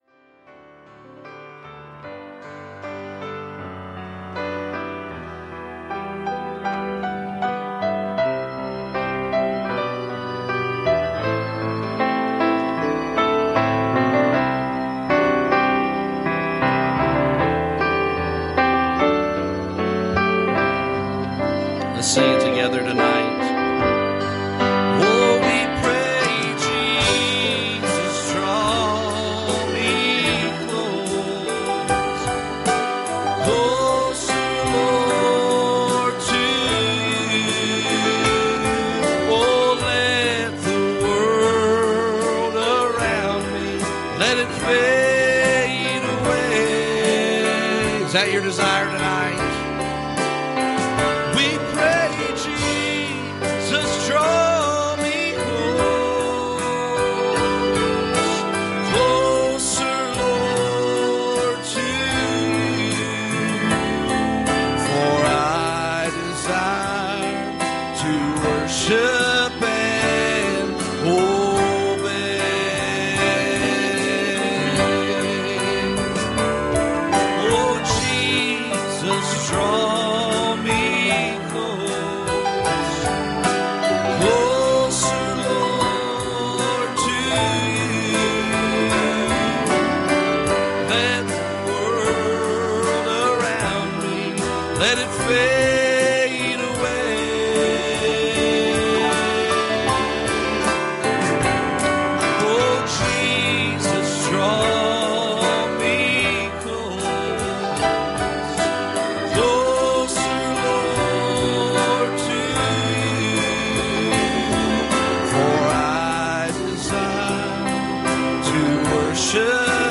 Service Type: Wednesday Evening